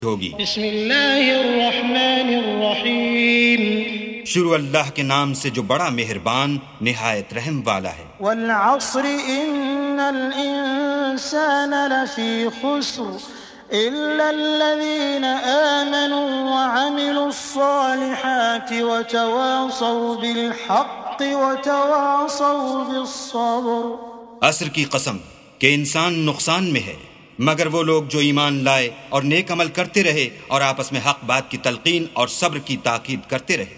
سُورَةُ العَصۡرِ بصوت الشيخ السديس والشريم مترجم إلى الاردو